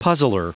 Prononciation du mot puzzler en anglais (fichier audio)
Prononciation du mot : puzzler